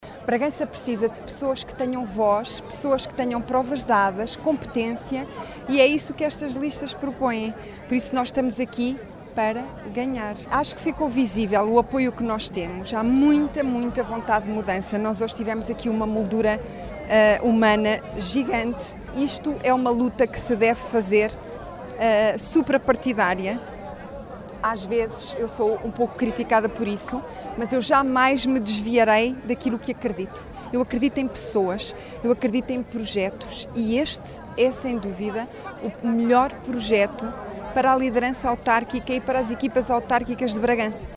“Bragança precisa de sangue novo e programas ambiciosos”, afirmou Isabel Ferreira durante a apresentação da lista à Câmara Municipal de Bragança, que decorreu na terça-feira.